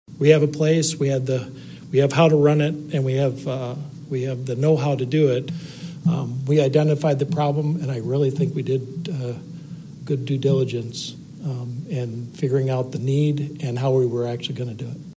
HOLLAND, MI (WHTC-AM/FM, Mar. 5, 2026) – Recycling was spotlighted in Wednesday night’s biweekly Holland City Council business meeting.